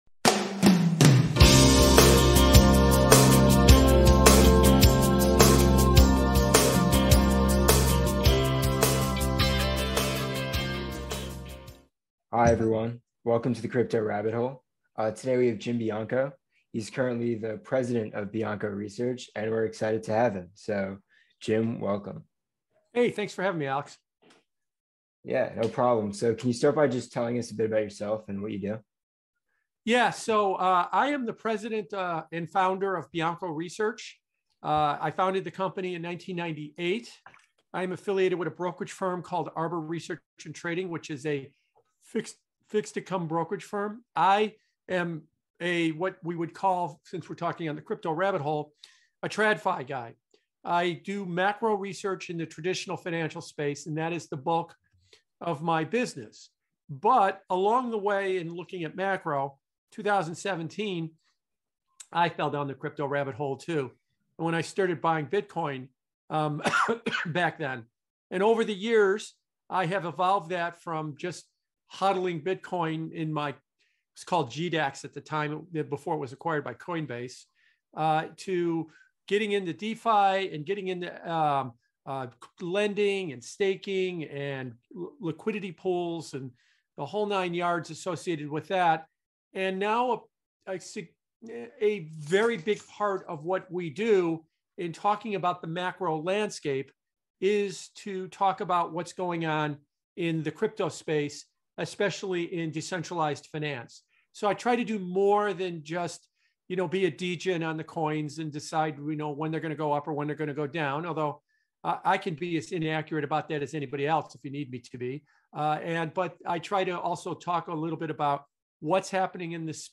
The Economy and Bitcoin - An Interview